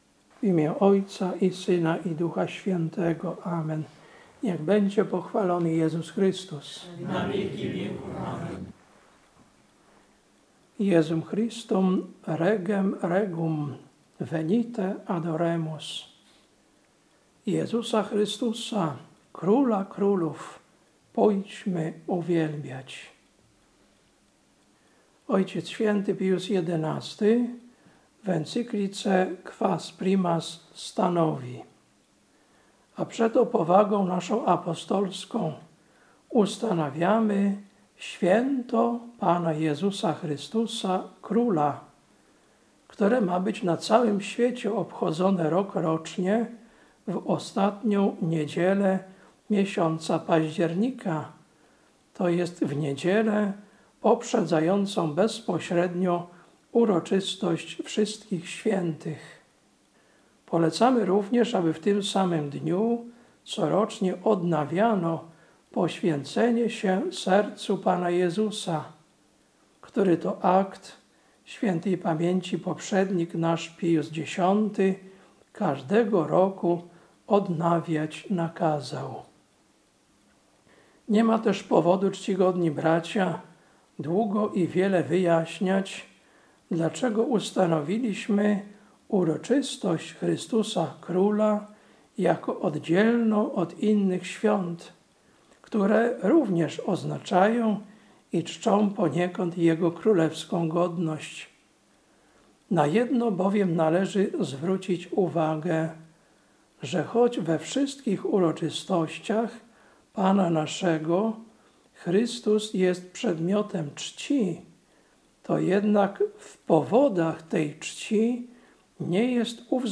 Kazanie na święto Chrystusa Króla, 26.10.2025 Ewangelia: J 18, 33-37